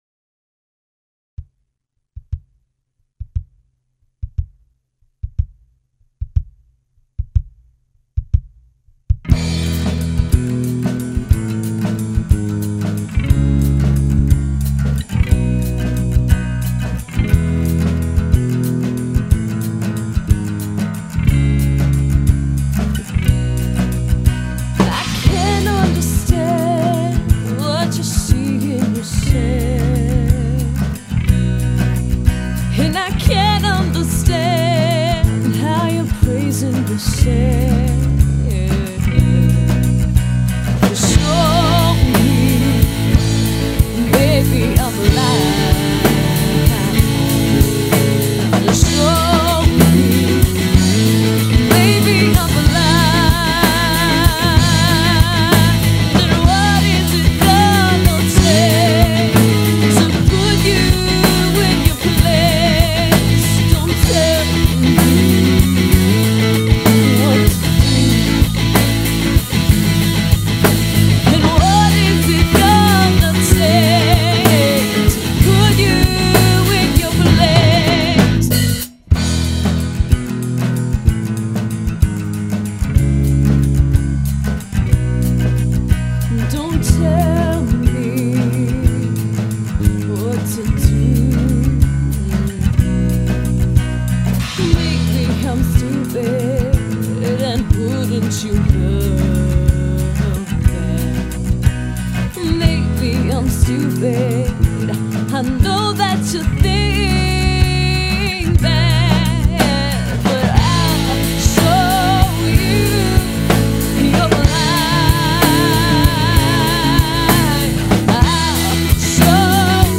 vocals
bass
drums